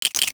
NOTIFICATION_Rattle_10_mono.wav